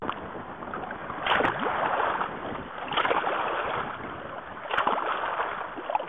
K-Pelit - Luontoääniä
Luonnon aiheuttamia ääniä.